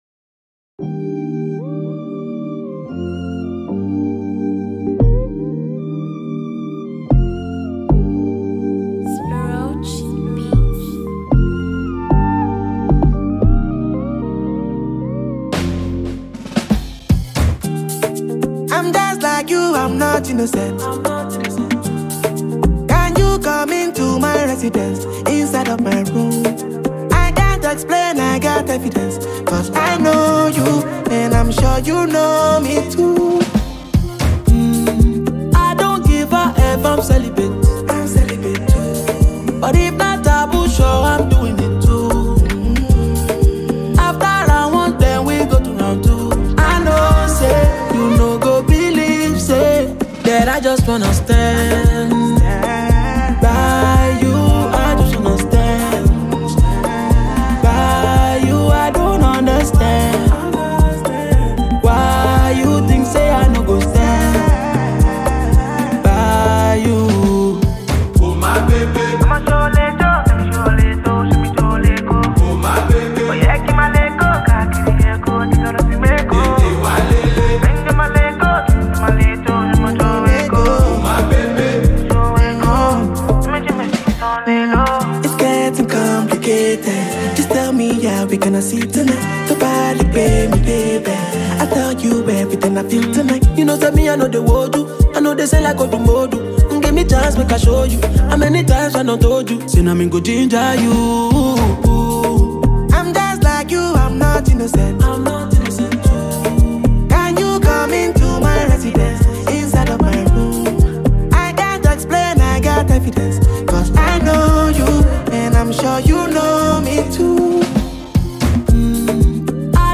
You will always remember this song’s wonderful melody.